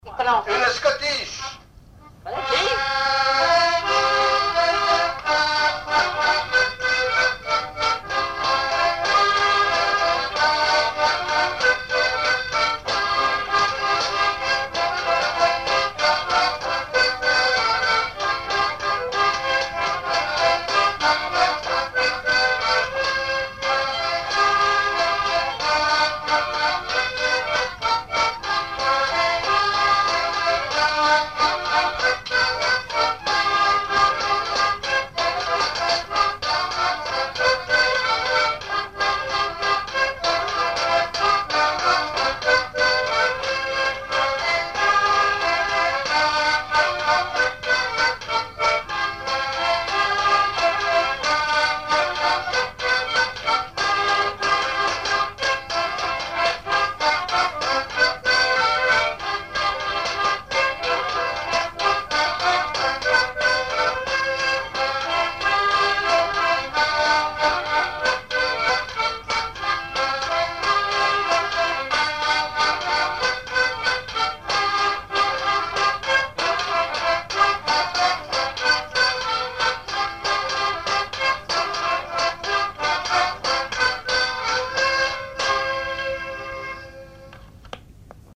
scottich trois pas
enregistrements du Répertoire du violoneux
Pièce musicale inédite